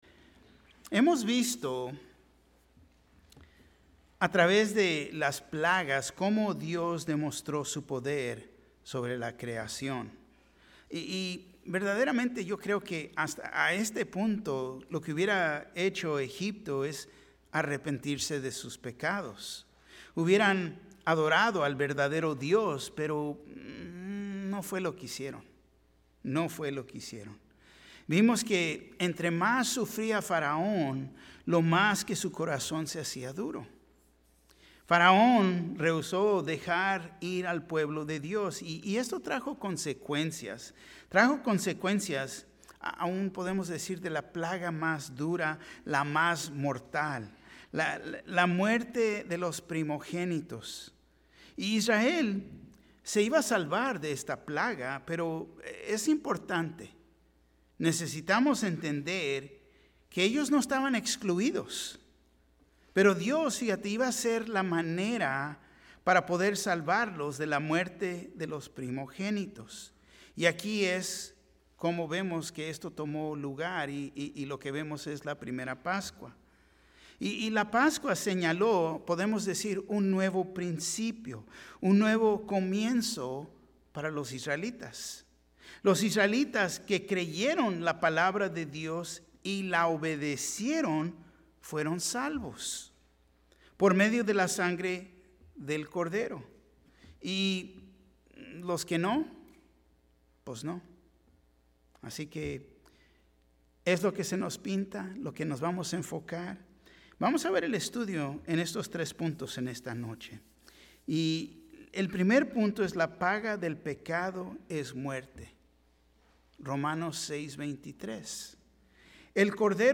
Un mensaje de la serie "Estudios Tématicos." El plan de Dios.